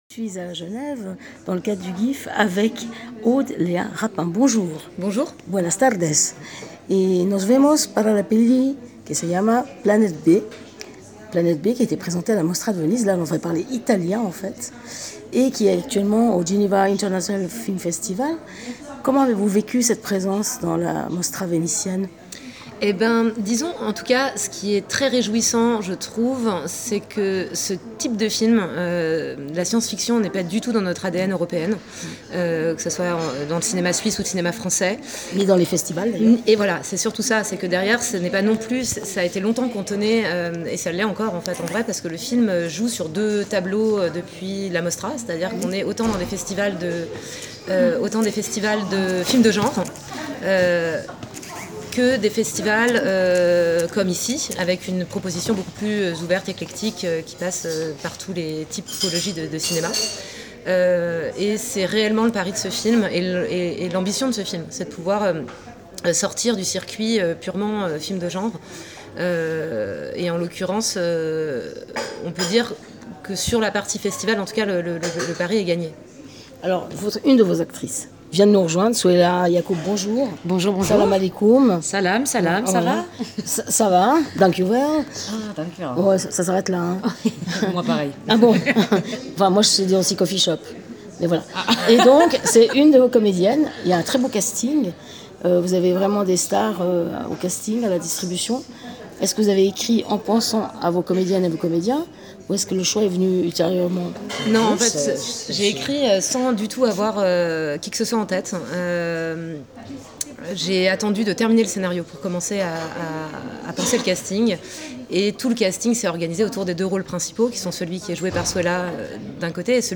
Planète B, un thriller de science-fiction qui suggère un avenir digne d’un jeu vidéo. Rencontre